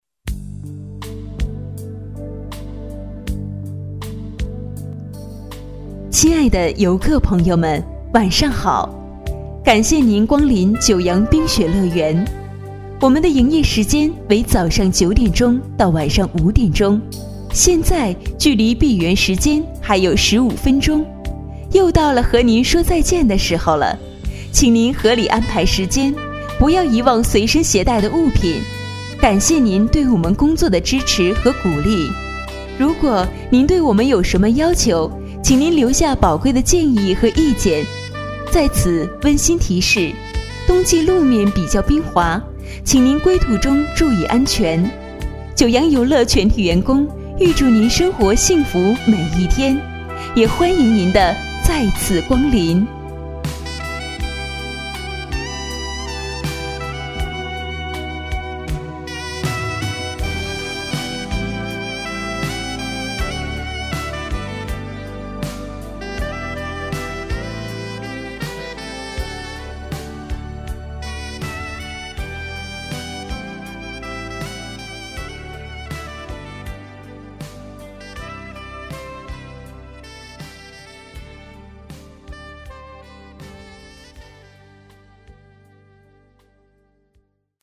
移动水上乐园之九洋冰雪乐园广播